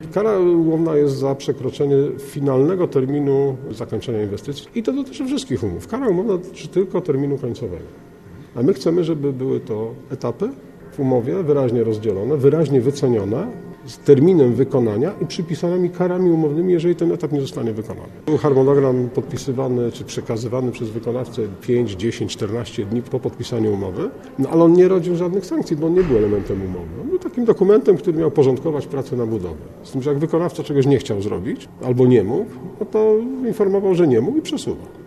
Naszym gościem był Miejski Inżynier Ruchu, Łukasz Dondajewski, który zatwierdza każdy remont w Poznaniu i odpowiada za organizację ruchu w mieście.